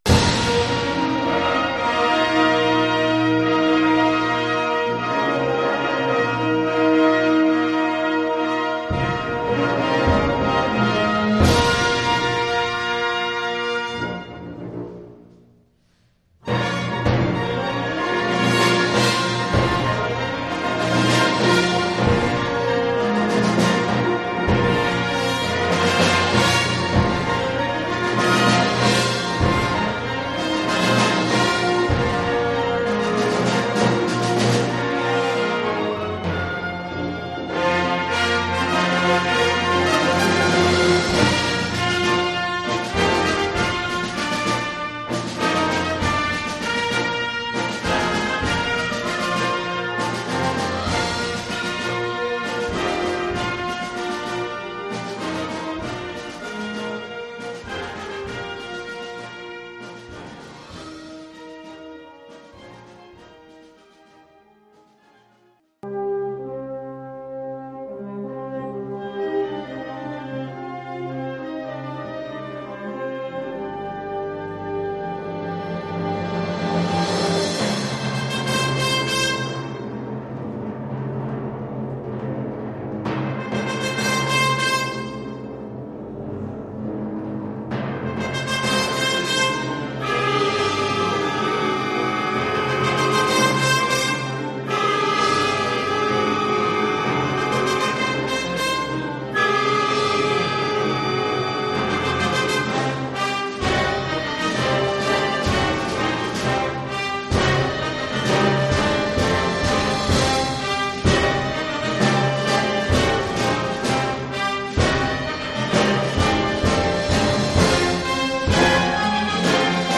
Genre musical : Classique
Collection : Harmonie (Orchestre d'harmonie)
Oeuvre pour orchestre d’harmonie.